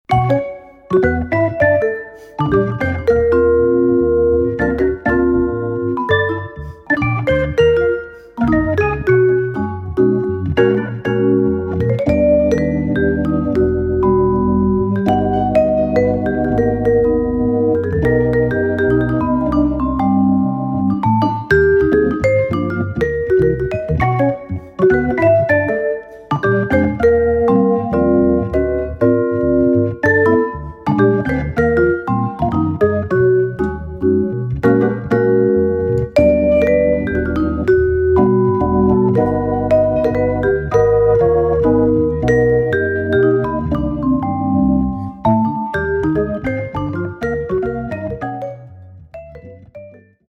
Jazz Duo
vibraphone
Hammond organ